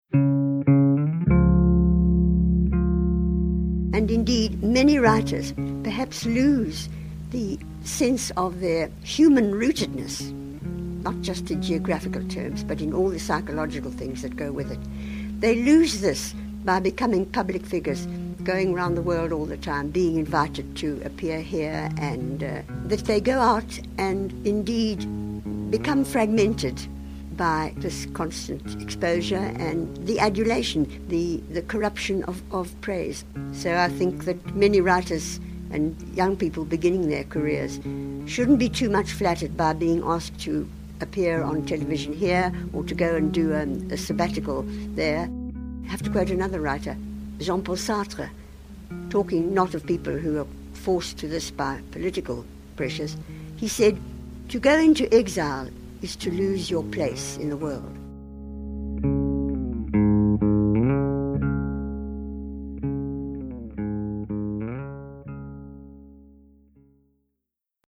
Nobel Prize-winning writer and political activist, Nadine Gordimer, on the importance of being rooted in one place.